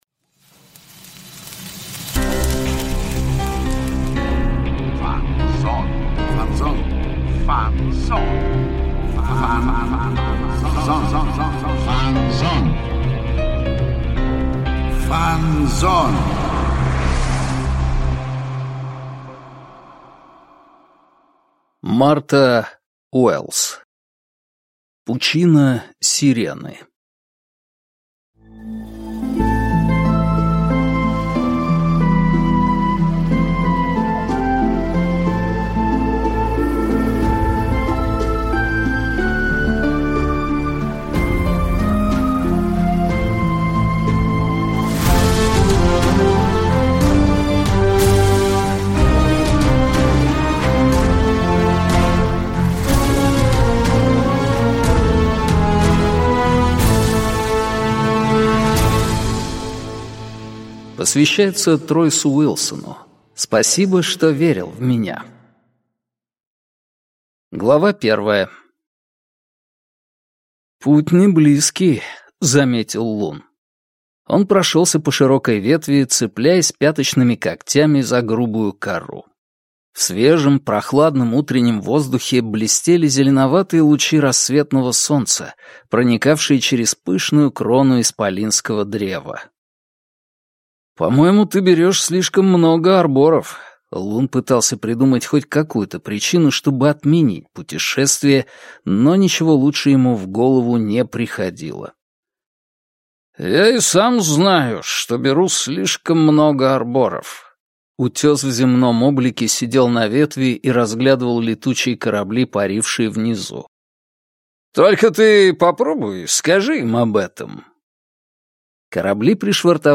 Аудиокнига Пучина Сирены | Библиотека аудиокниг